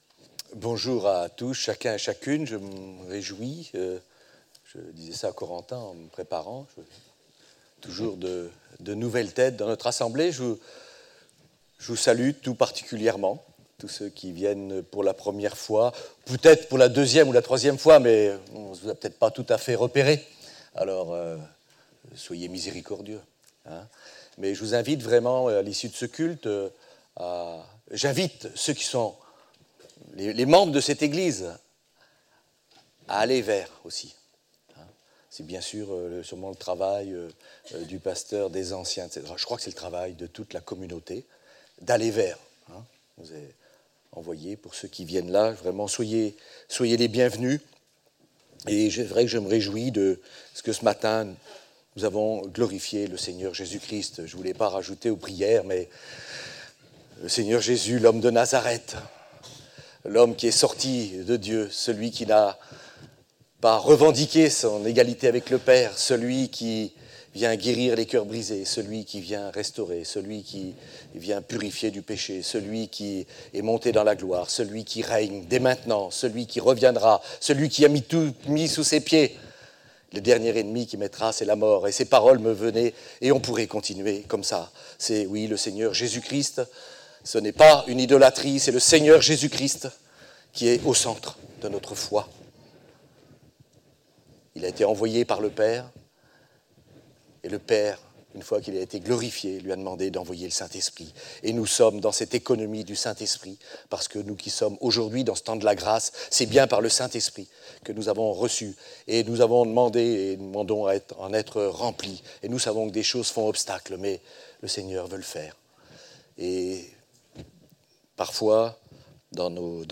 "Courons" 15 octobre, 2017 Je te donne le résumé de la prédication de ce matin : Hébreux 12-1 nous encourage à courir : ‘’ courons avec persévérance l'épreuve qui nous est proposée ‘’ et donc comme pour un sportif de nous poser la question de ce qui va nous aider à accomplir la course de notre vie sur terre.